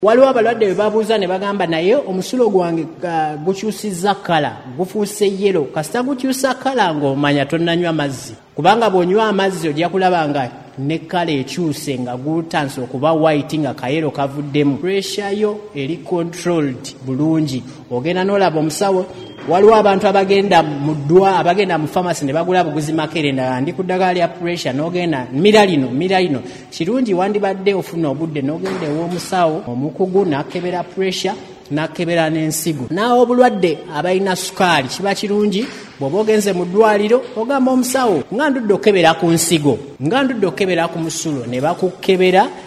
Speaking during the launch on Tuesday